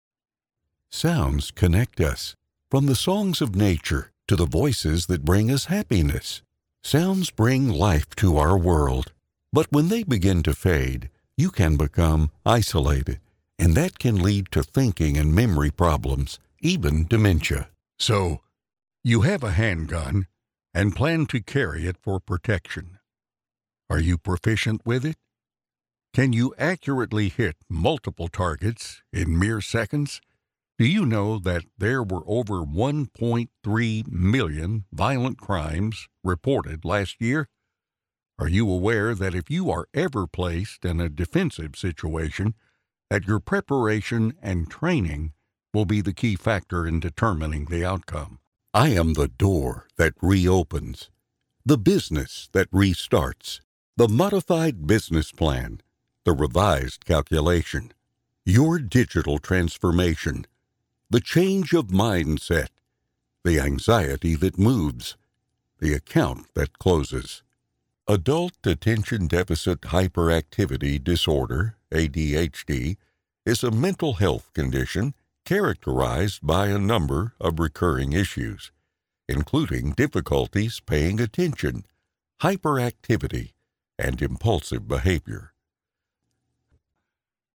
A deep male professional voice, with a 30-60 age range, warm, authoritative, relatable & conversational performances ideal for Christian content and epic Voice of God styles.